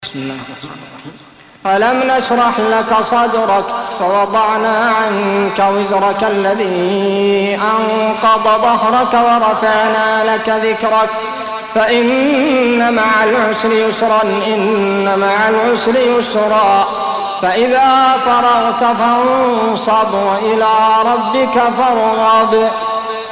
Audio Quran Tarteel Recitation
Surah Sequence تتابع السورة Download Surah حمّل السورة Reciting Murattalah Audio for 94. Surah Ash-Sharh سورة الشرح N.B *Surah Includes Al-Basmalah Reciters Sequents تتابع التلاوات Reciters Repeats تكرار التلاوات